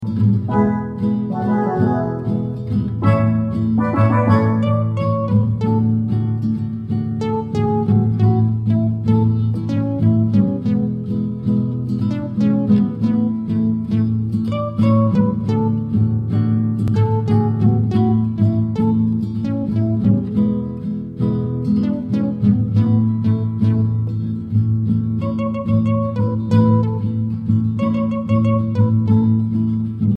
Listen to the instrumental backup track.